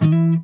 OCTAVE 2.wav